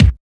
TOUGH KICK.wav